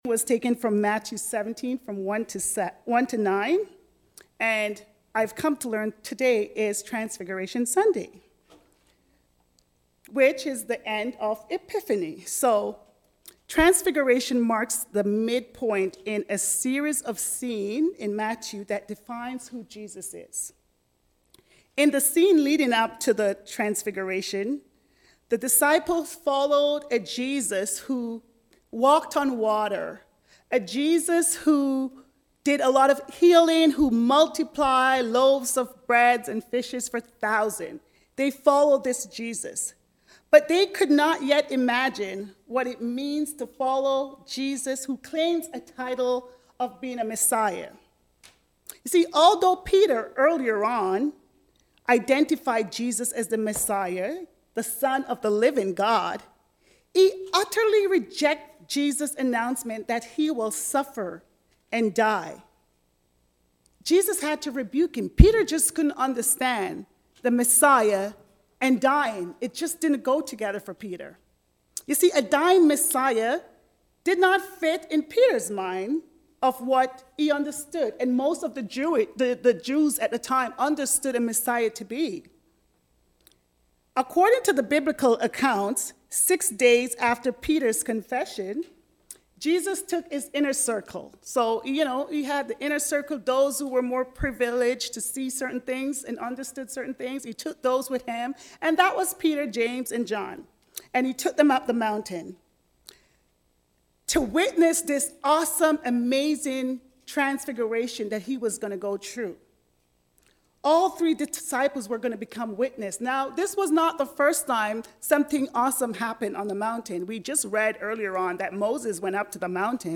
Releasing our Preconceptions. A sermon on the Transfiguration
Sermon-Transfiguration-Sunday.mp3